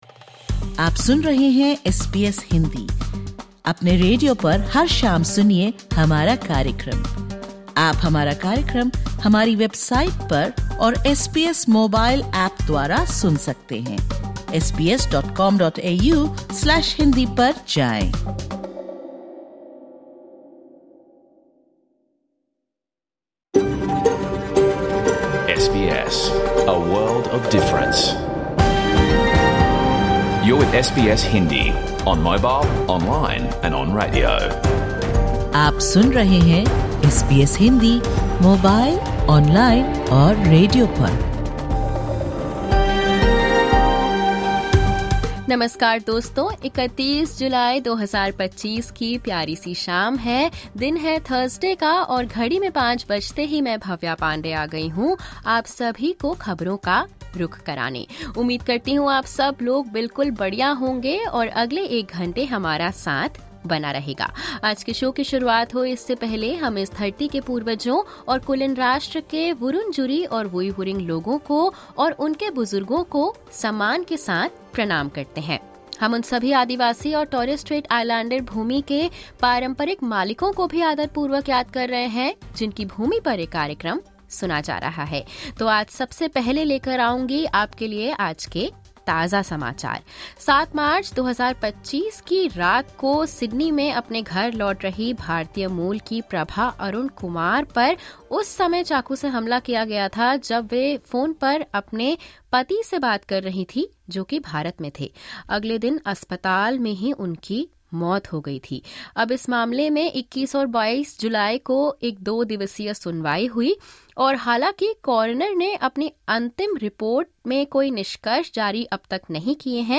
Listen to the full SBS Hindi radio program from 31 July 2025.